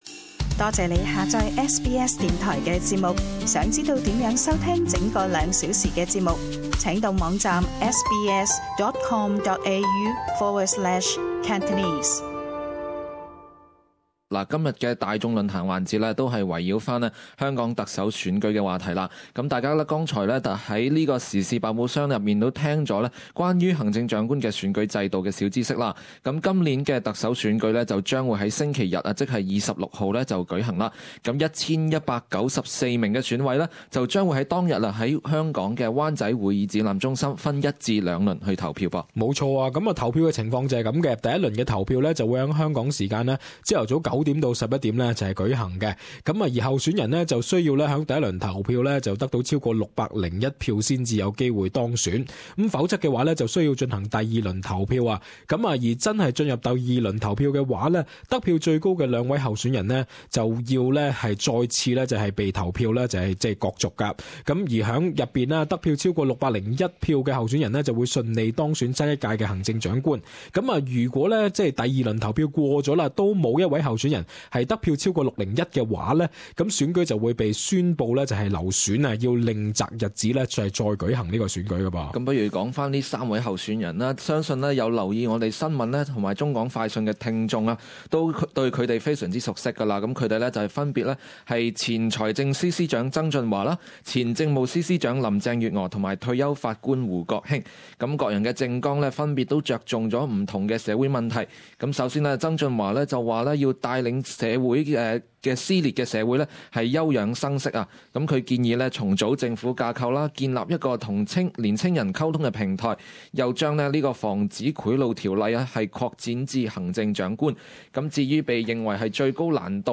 Talkback: The 2017 Hong Kong Chief Executive Election